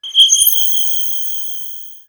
RetroGamesSoundFX